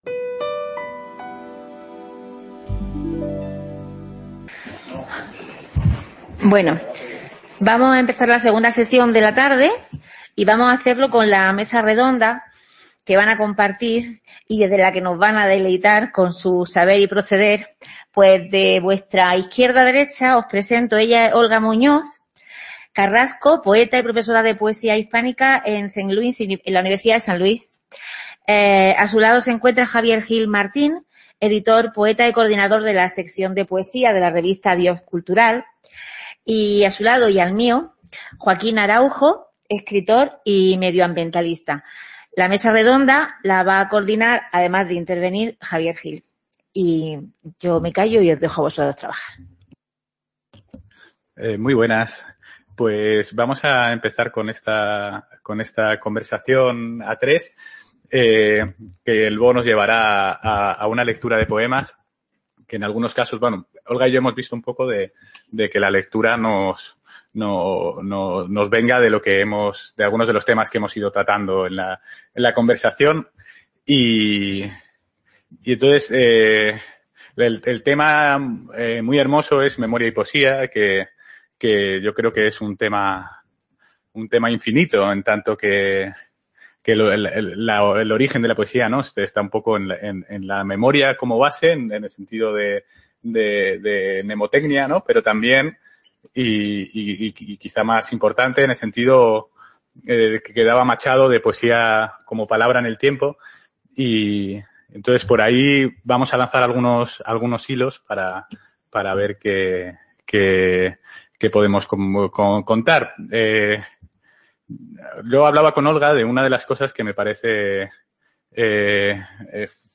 Poesía y Memoria. Mesa redonda | Repositorio Digital